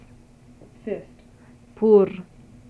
Sound File #2 - The word pronounced in Western Armenian is, "Fist". The consonant produced in the word is pʰ. This sound file contains both the English and Armenian pronunciation of the word. Sound file is collected from a larger sound file located in the UCLA Phonetics Archive.